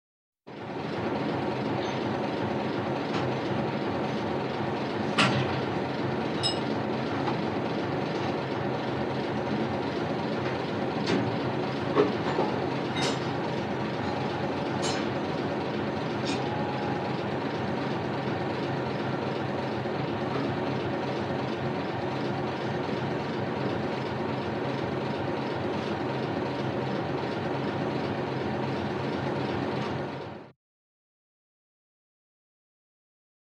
Катера звуки скачать, слушать онлайн ✔в хорошем качестве
Корабль: гул большого корабля (запись в салоне) Скачать звук music_note Катера , Корабли , Лодки save_as 488.3 Кб schedule 0:31:00 6 0 Теги: mp3 , в салоне , Водный транспорт , гудение , Гул , звук , корабли , лодки , Шум